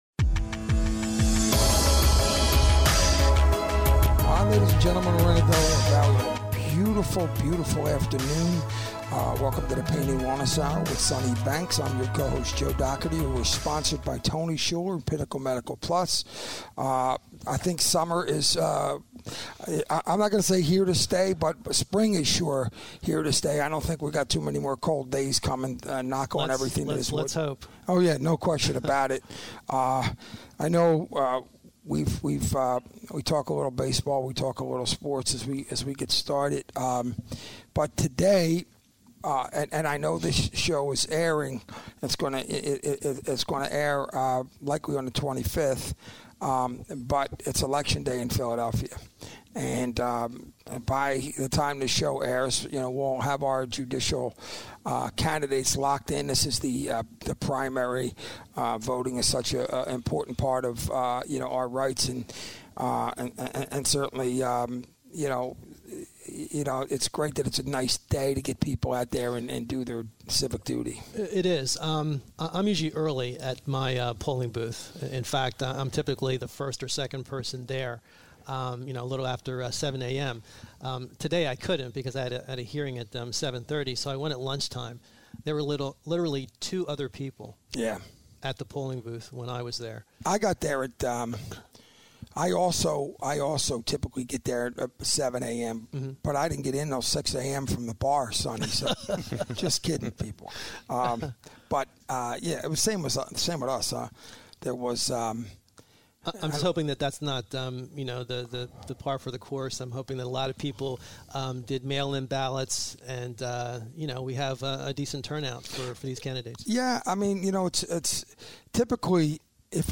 talk radio show